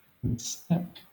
Ääntäminen
Southern England
IPA : /ˈmɪs.stɛp/